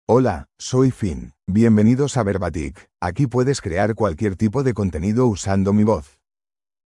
Finn — Male Spanish (Spain) AI Voice | TTS, Voice Cloning & Video | Verbatik AI
Finn is a male AI voice for Spanish (Spain).
Voice sample
Listen to Finn's male Spanish voice.
Finn delivers clear pronunciation with authentic Spain Spanish intonation, making your content sound professionally produced.